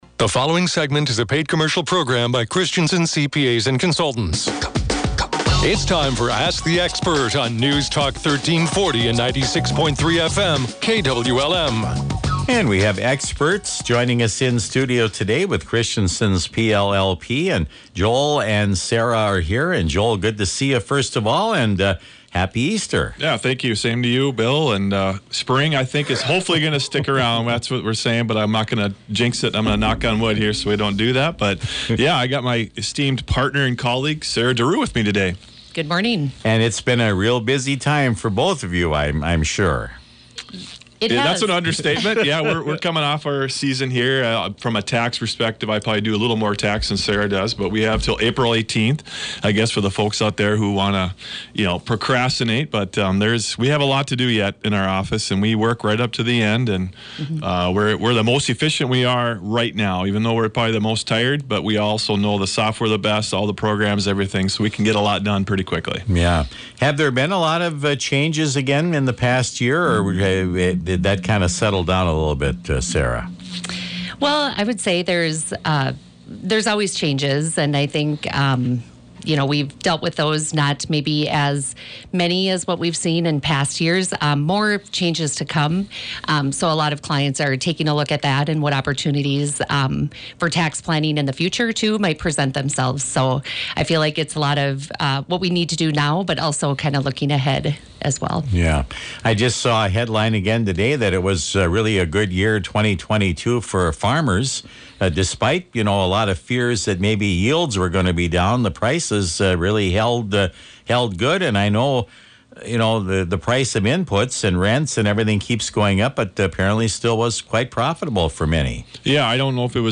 Ask The Experts Segment